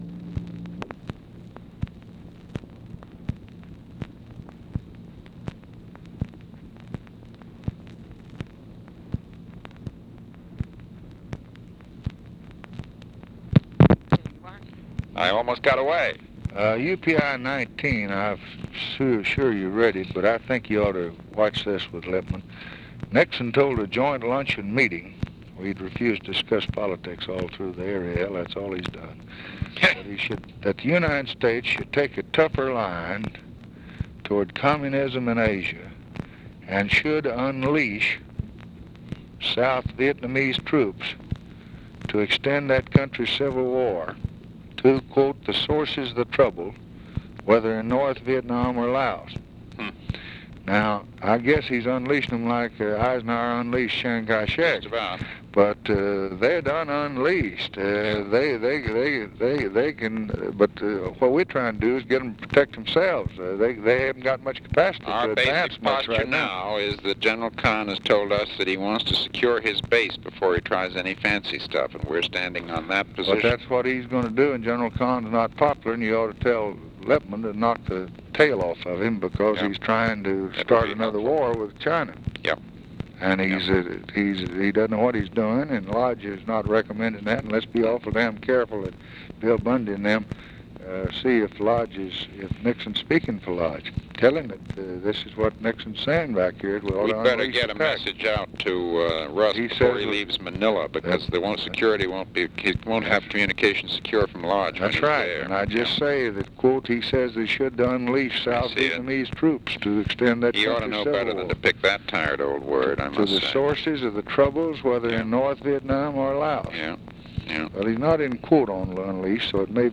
Conversation with MCGEORGE BUNDY, April 14, 1964
Secret White House Tapes